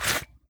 Leather Unholster 003.wav